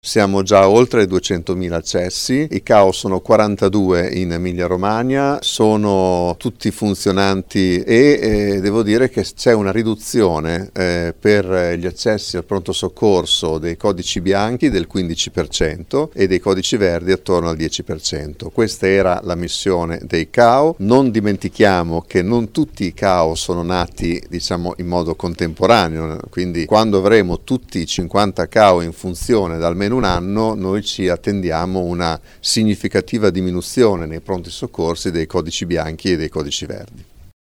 Ma sentiamo proprio sui CAU l’assessore regionale alla sanità, Raffaele Donini: